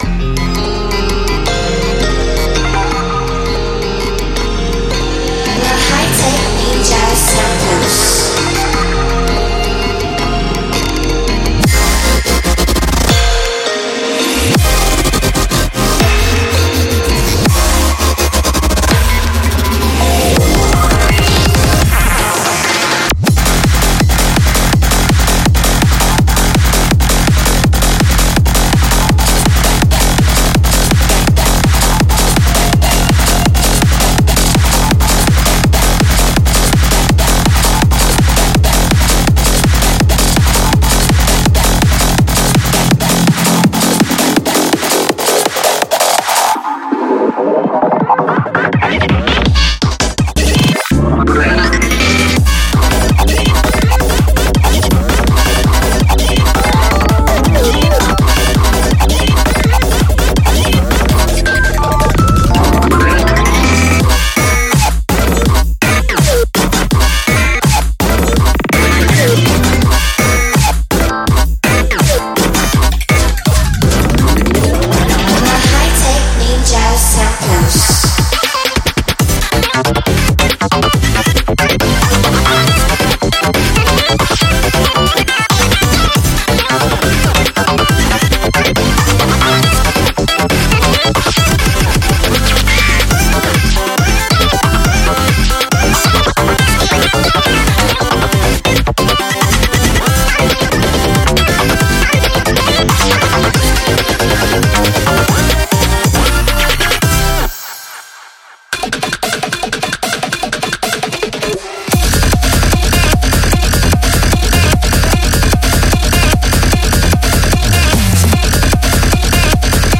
◇Acid
•HiTECH BassLine Loops x 41
◇Drums
◇Effects
◇Synths
•Vocals and Shouts x 58